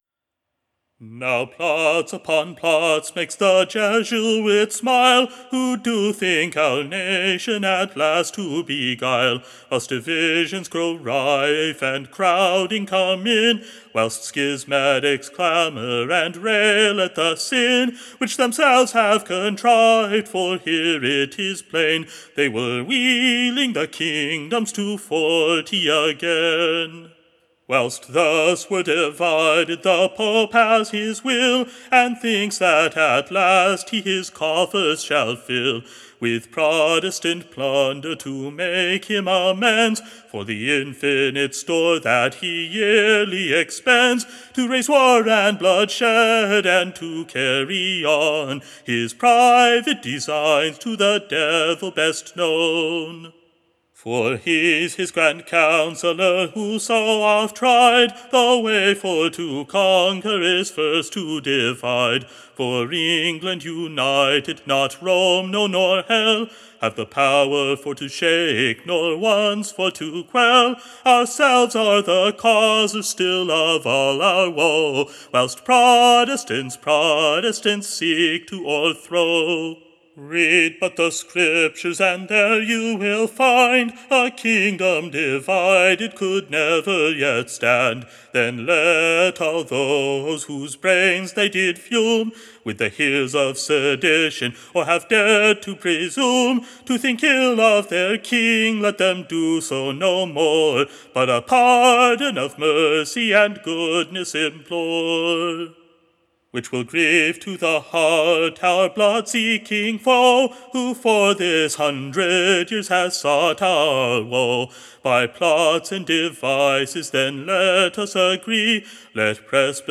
/ A Pleasant New SONG.